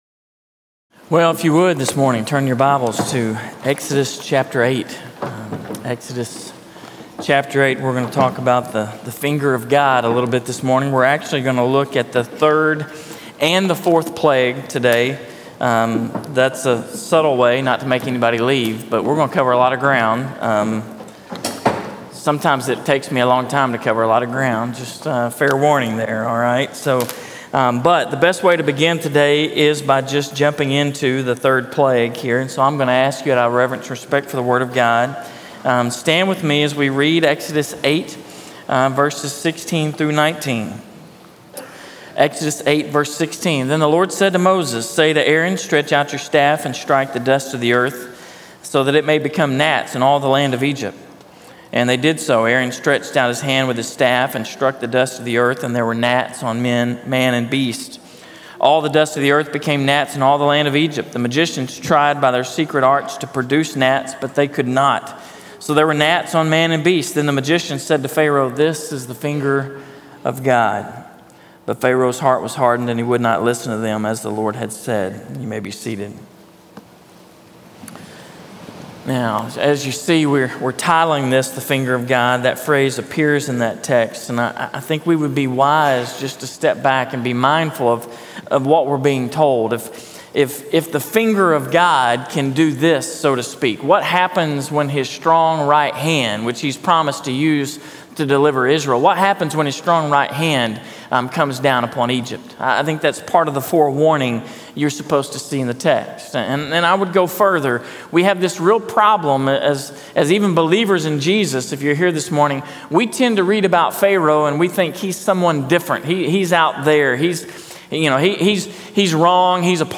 In this message, we move into week four of our sermon series, “The Deliverance,” from Exodus, and we consider the third and fourth plagues. We are soberly confronted with the truth that these actions represent only “the finger of God,” or the smallest bit of His majesty and power.